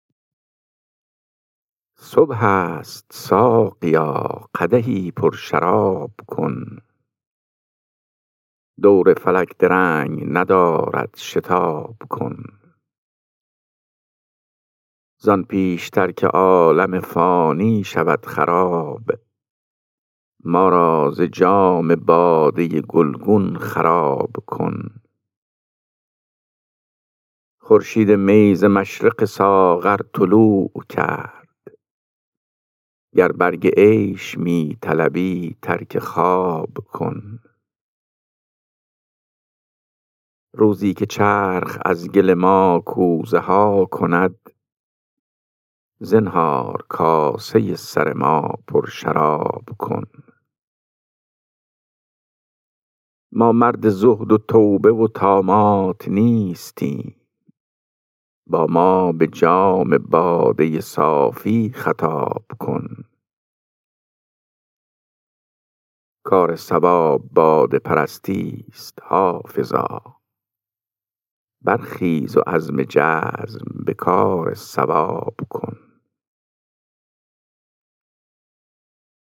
خوانش غزل شماره 396 دیوان حافظ